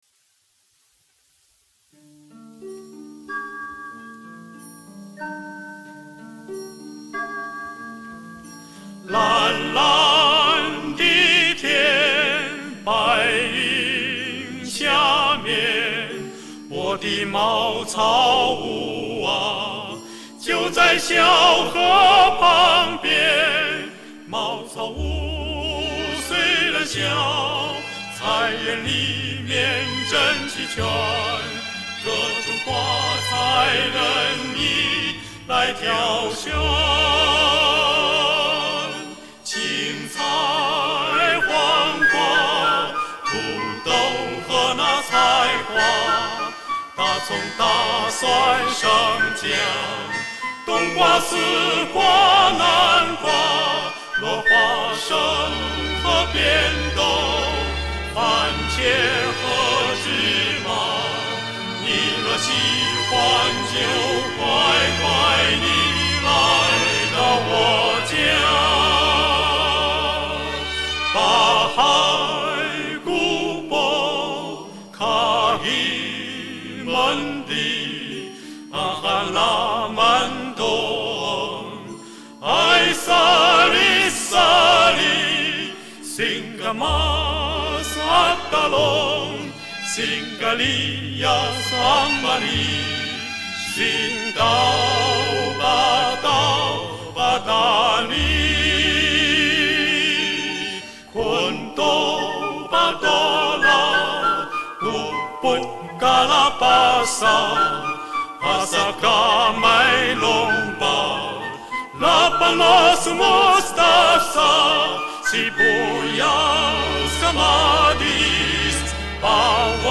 [14/6/2017]中央乐团男声四重唱《茅草屋》（菲律宾歌曲） 激动社区，陪你一起慢慢变老！